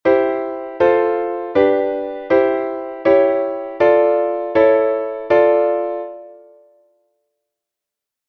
1. C-Dur zu d-Moll
Chromatische Modulation C- d
Das C wird zum Cis und es entsteht sofort eine Spannung, die nach Auflösung strebt.
Chromatische-Modulation-C-d.mp3